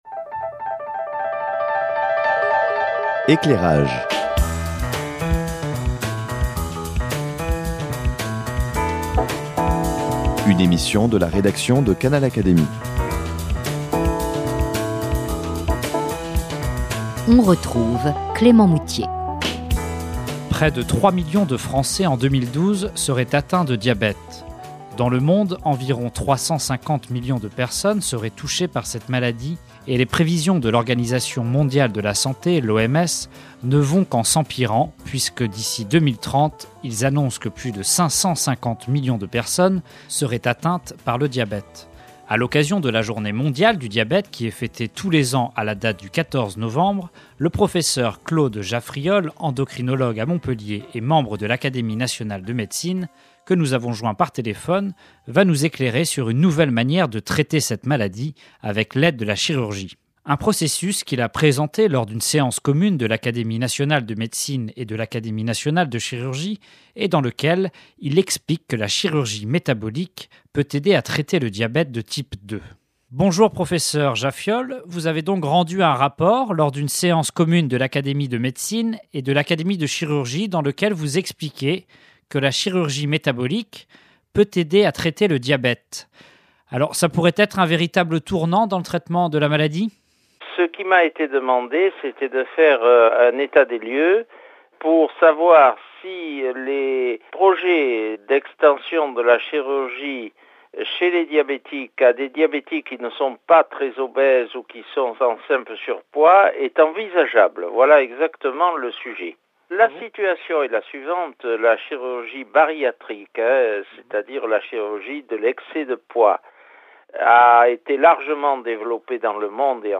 Joint par téléphone, l’académicien nous présente ce qui pourrait être "un tournant décisif" dans le traitement de la maladie.